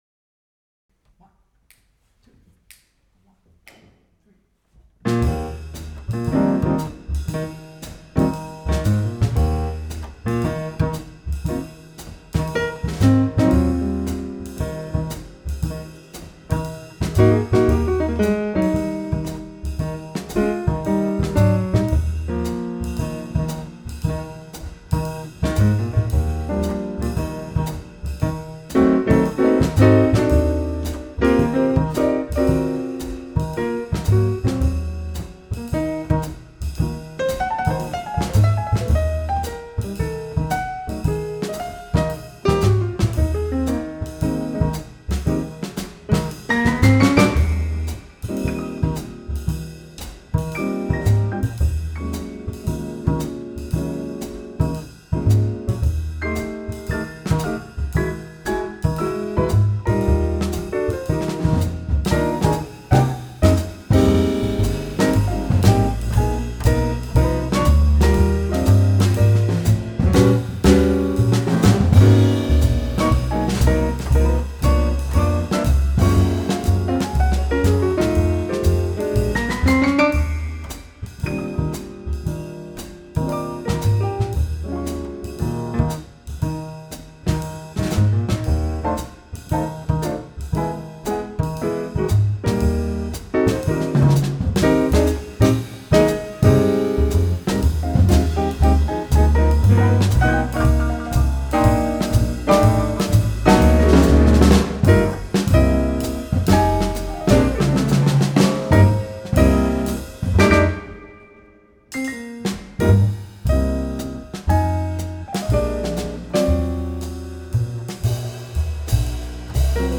Small Band. Big Sound.